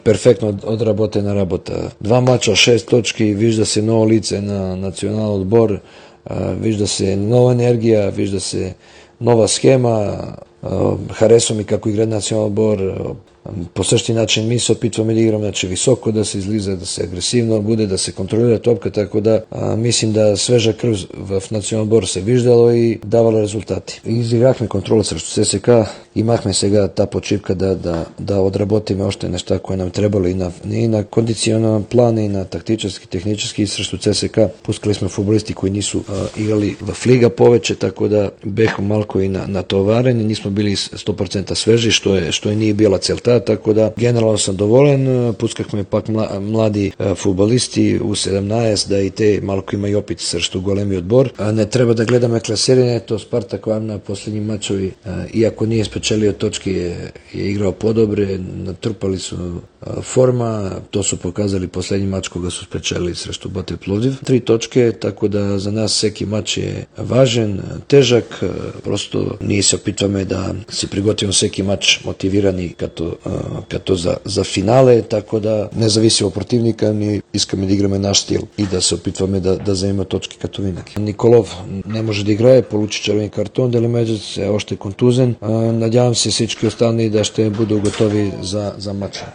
говори за Дарик радио и dsport преди домакинския мач срещу Спартак Варна